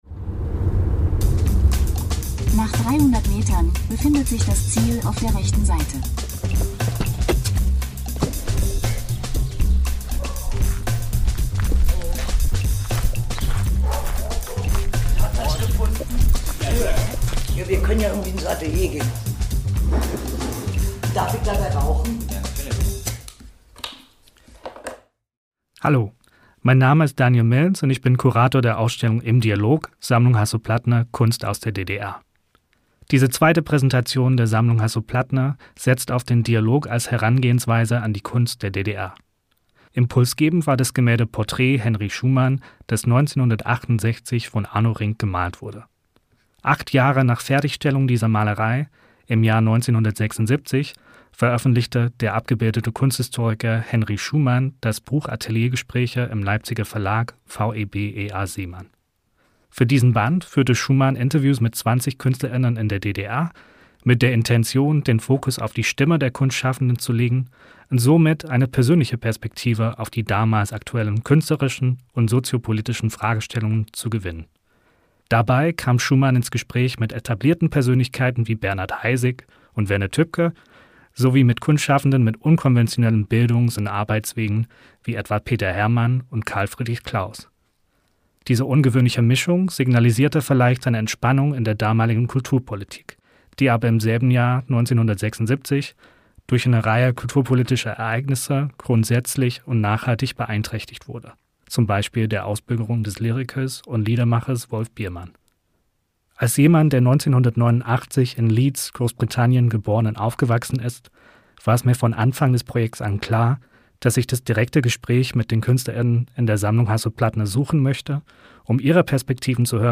in ihrem Atelier auf dem Land. Im Gespräch entfaltet sie eindrucksvoll, wie ihre Kunst aus biografischen Brüchen, innerer Widerspenstigkeit und radikaler Eigenständigkeit entsteht.